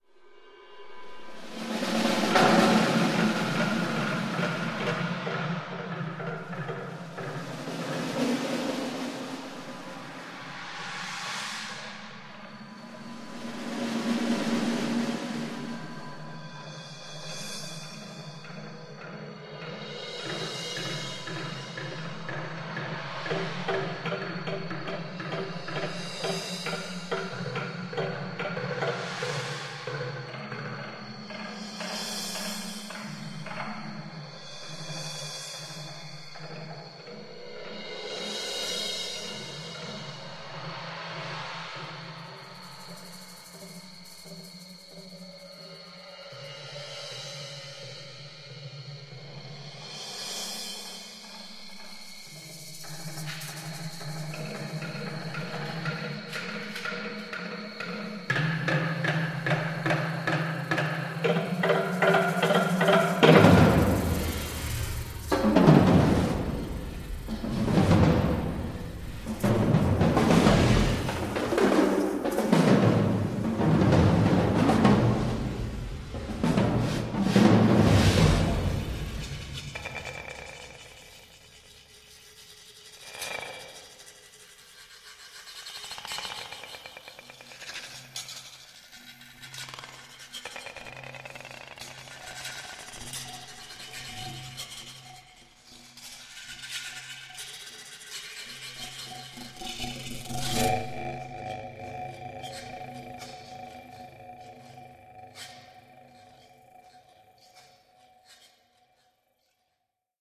for percussion sextet
and electronic
surround sounds